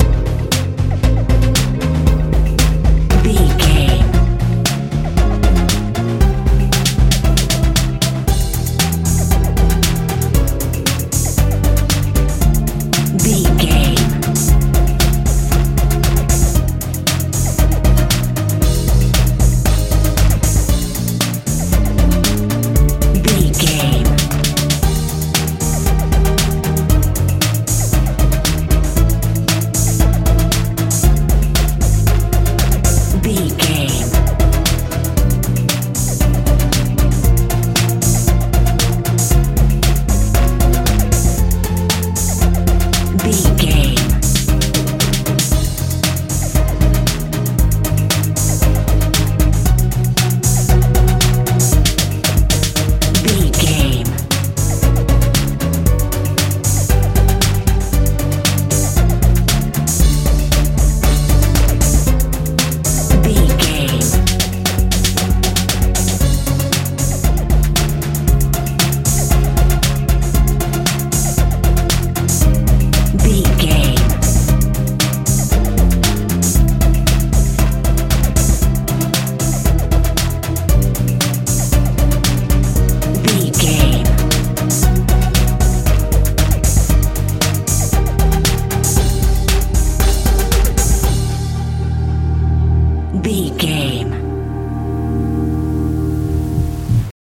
jpop feel
Aeolian/Minor
energetic
synthesiser
bass guitar
drums
80s